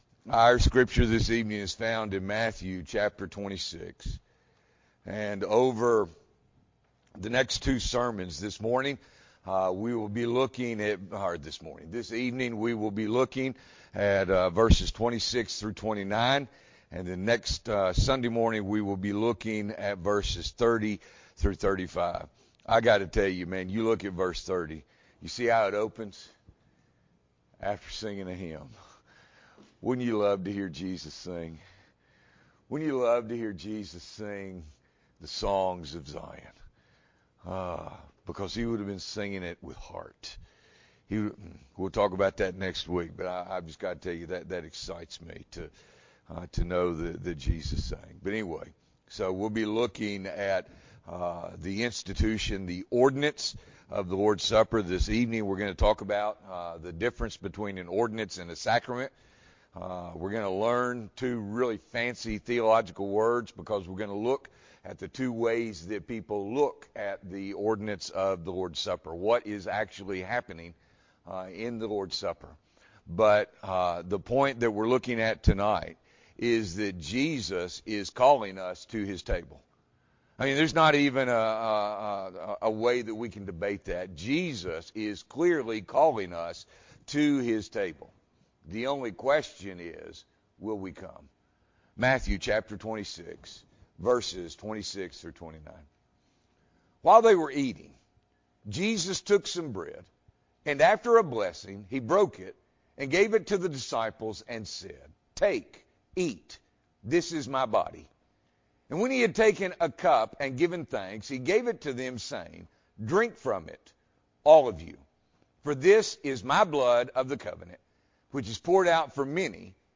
January 31, 2021 – Evening Worship